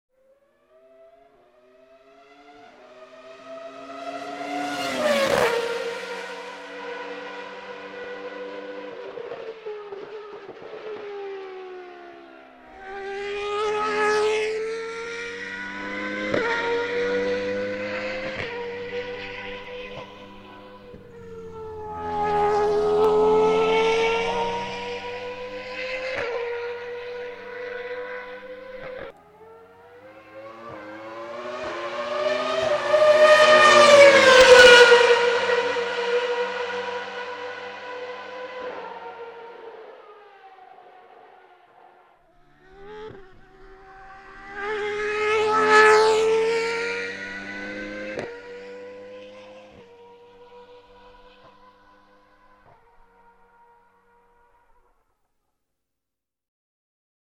La Ferrari durante un giro per la pole position: 60 secondi di accelerazioni e staccate al limite.